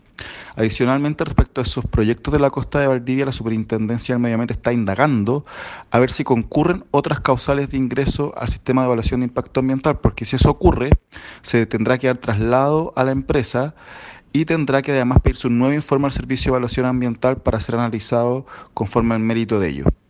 Por su parte, el fiscal de la Superintendencia del Medio Ambiente, Emanuel Ibarra, dijo que están indagando otras causales para que dichos loteos ingresen al Sistema de Evaluación Ambiental.